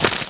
glass_i1.wav